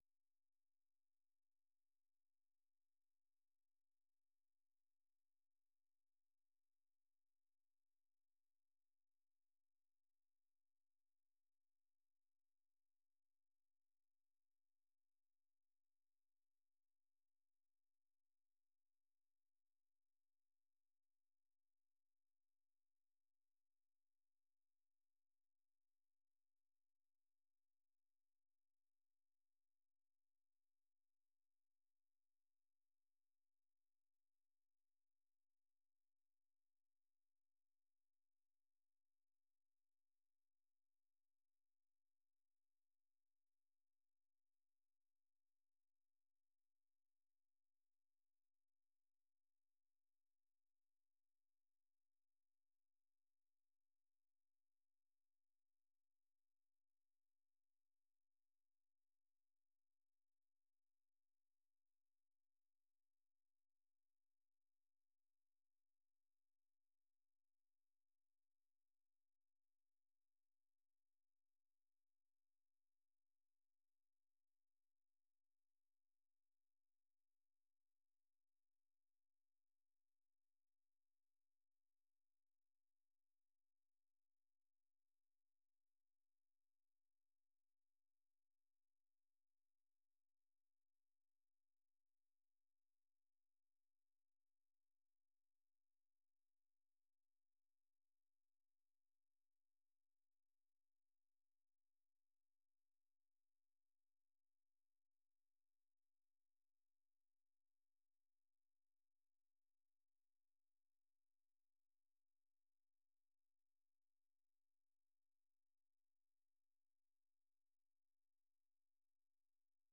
세계 뉴스와 함께 미국의 모든 것을 소개하는 '생방송 여기는 워싱턴입니다', 아침 방송입니다.